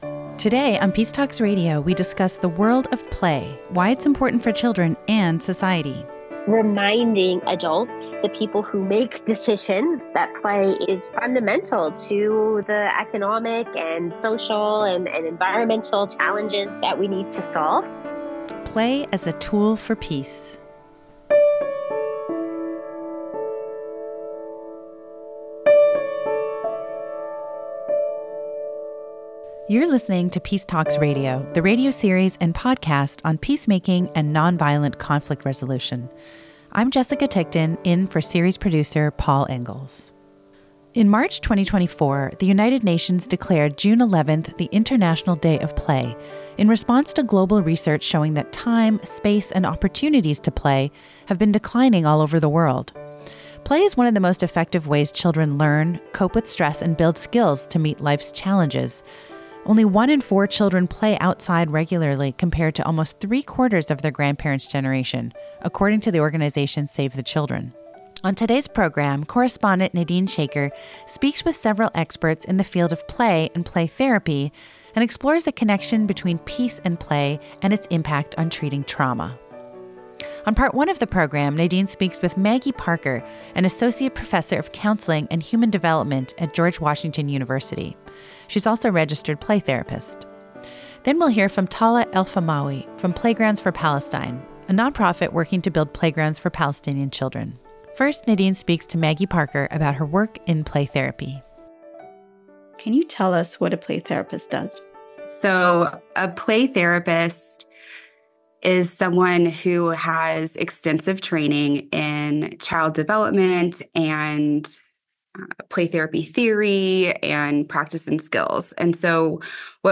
In this Peace Talks Radio program, we explore the connection between peace and play, and its impact on treating trauma.